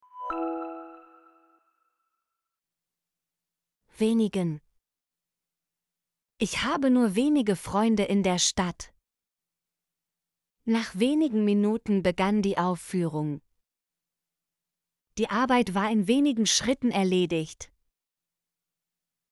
wenigen - Example Sentences & Pronunciation, German Frequency List